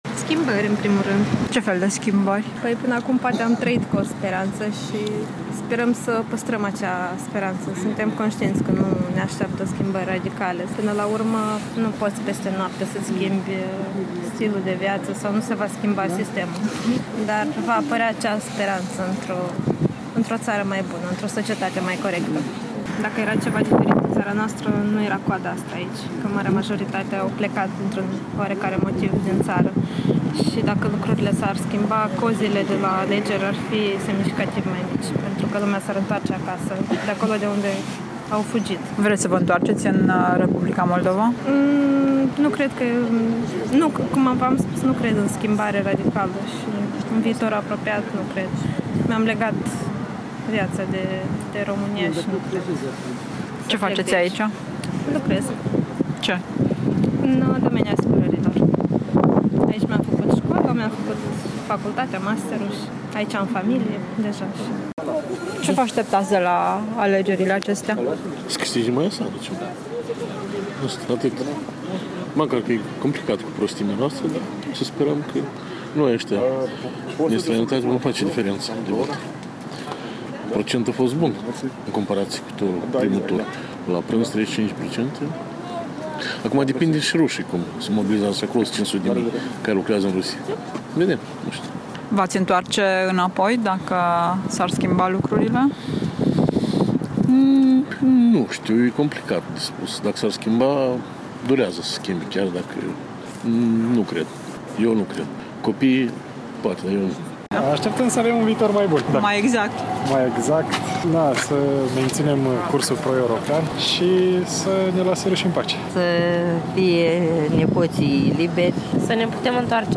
Imagini și voci culese la București.